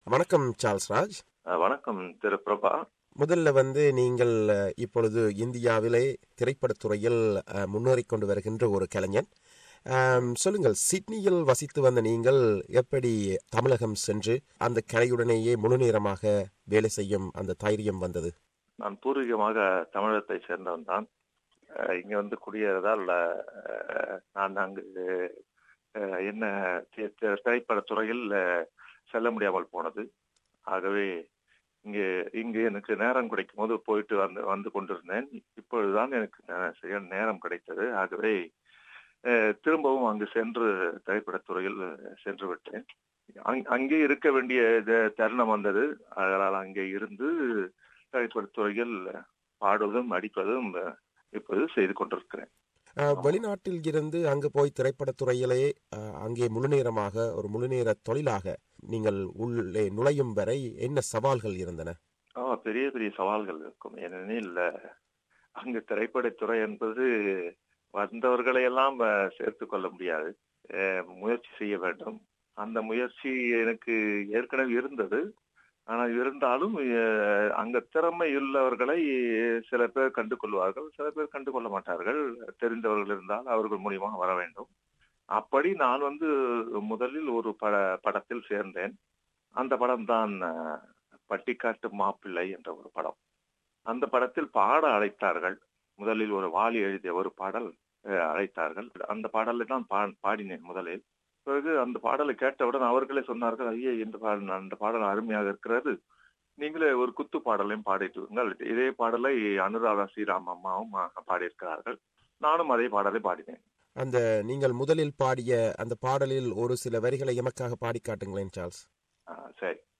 அவரைச் சந்தித்து உரையாடுகிறார்